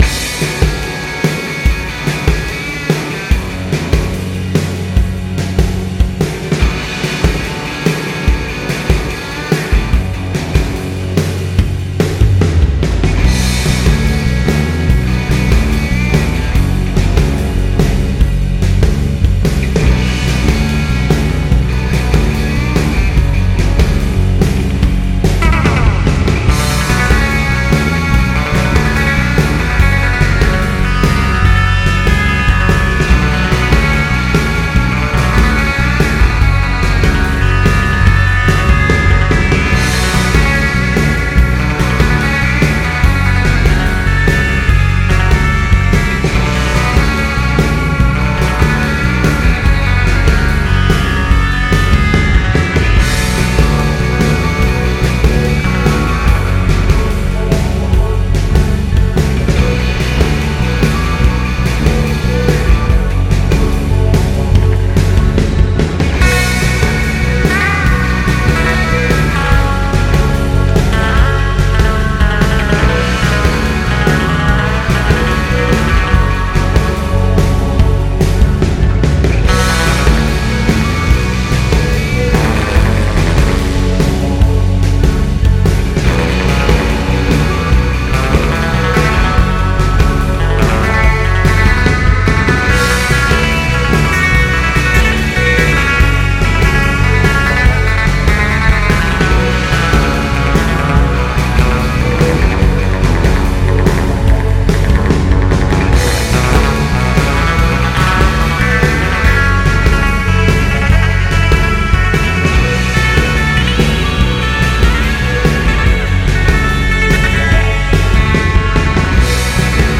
background music for a long driving scene
spooky surf rock song
i played the bass and the guitar.
i had to record the bass direct and the guitar in my closet. i dont really play the drums so theyre kind of basic.